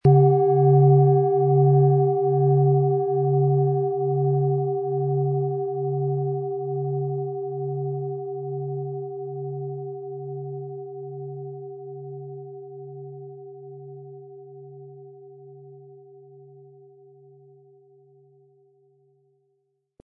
Von Hand getriebene Schale mit dem Planetenton Tageston.
Unter dem Artikel-Bild finden Sie den Original-Klang dieser Schale im Audio-Player - Jetzt reinhören.
Durch die traditionsreiche Fertigung hat die Schale vielmehr diesen kraftvollen Ton und das tiefe, innere Berühren der traditionellen Handarbeit
SchalenformBihar
MaterialBronze